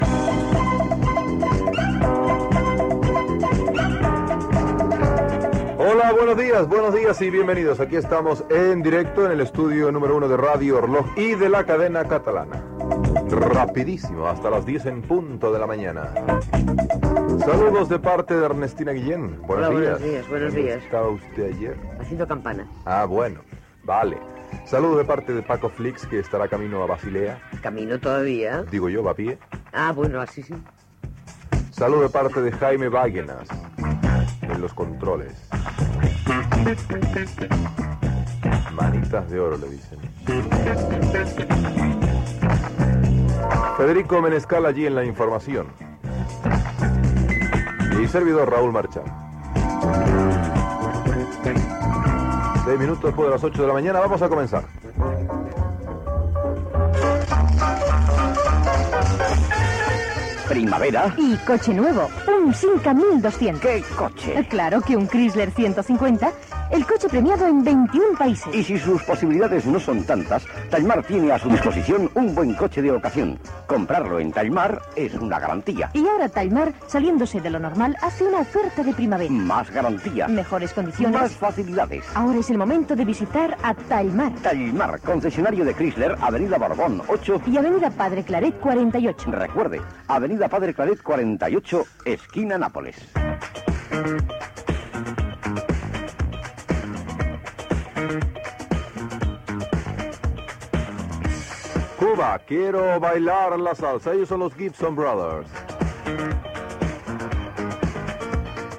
Presentació amb els noms de l'equip, hora, publicitat i primera cançó
Entreteniment